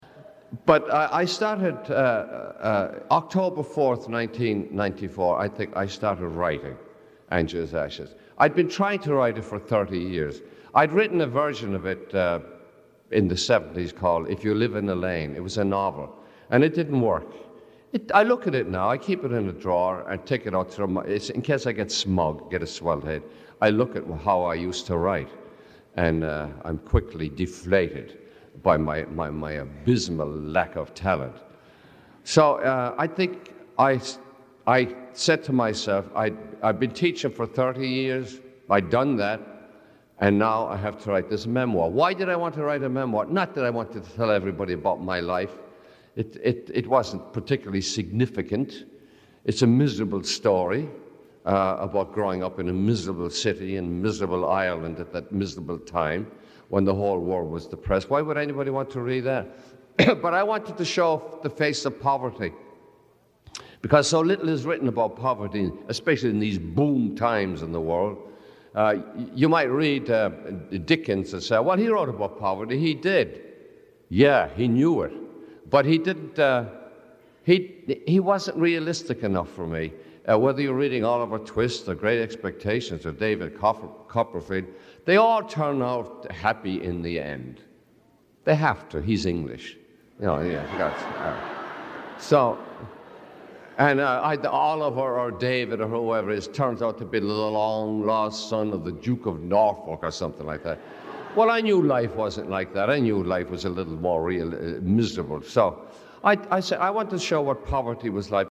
Lecture Title